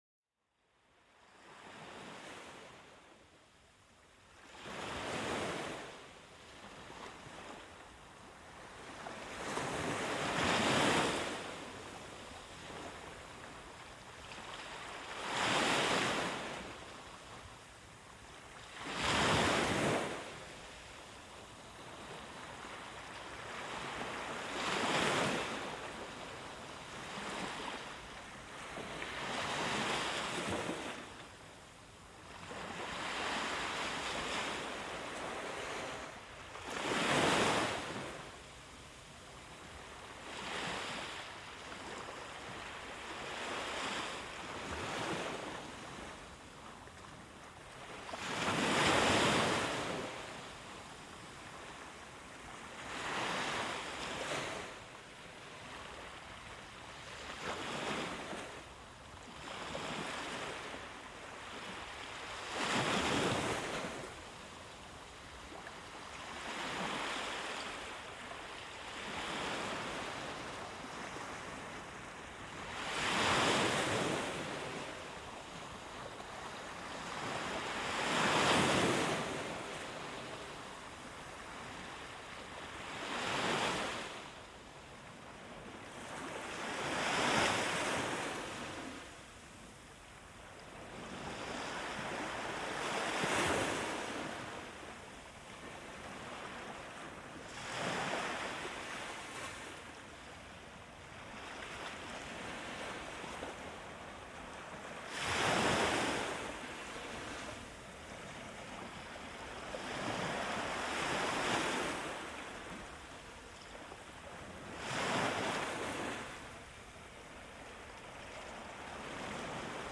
Plongez dans la mélodie apaisante des vagues s'échouant doucement sur le rivage. Chaque roulis d'eau invite à un moment de calme profond et de connexion avec la nature.
Chaque épisode vous enveloppe d'une ambiance apaisante, idéale pour calmer l'esprit et réduire le stress.